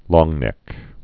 (lôngnĕk, lŏng-)